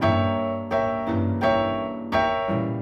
GS_Piano_85-G1.wav